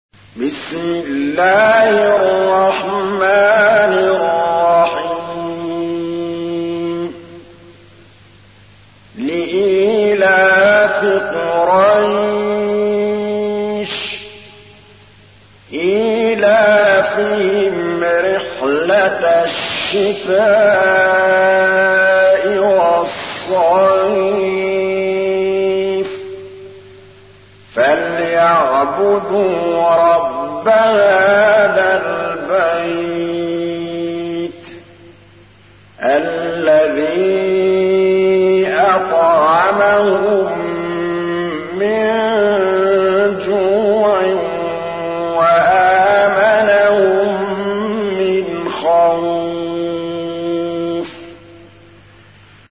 تحميل : 106. سورة قريش / القارئ محمود الطبلاوي / القرآن الكريم / موقع يا حسين